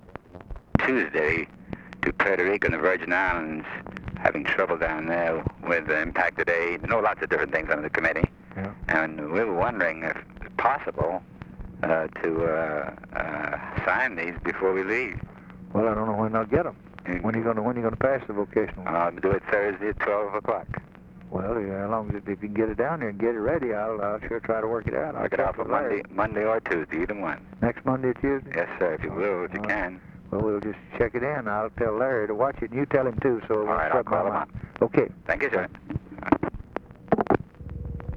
Conversation with ADAM CLAYTON POWELL, December 10, 1963
Secret White House Tapes